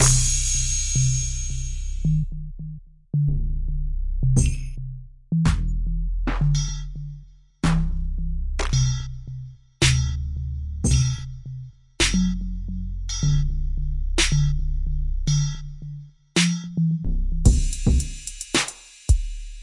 描述：循环嘻哈音轨旋律 嘻哈